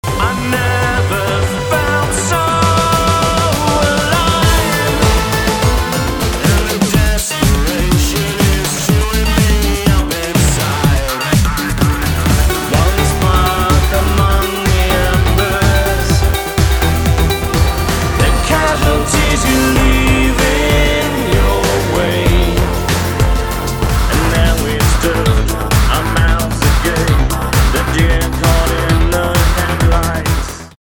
We mix the sampler but it’s too late, we are engulfed: